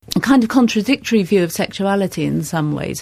The deaccentuation of final sentence adverbials is, in any case, a very likely possibility, as the following examples show: